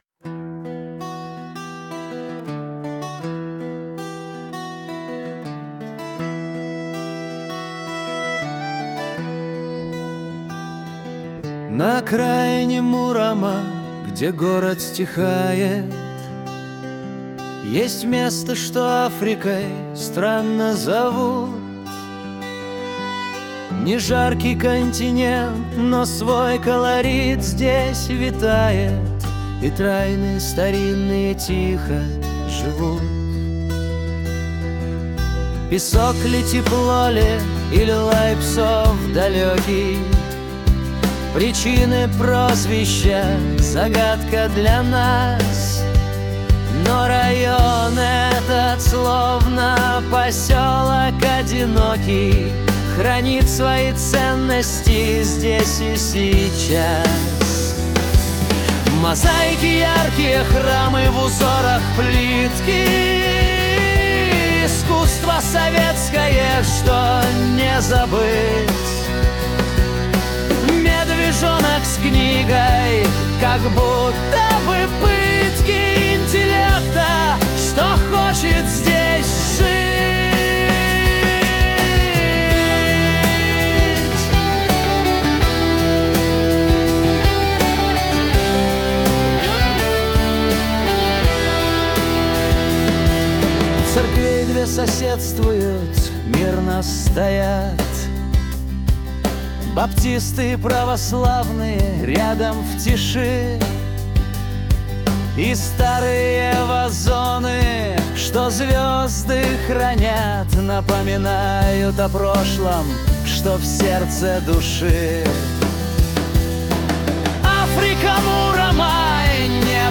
Текст: Муром Красивый feat. AI
Исполнитель: ИИ